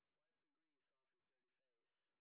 sp05_street_snr30.wav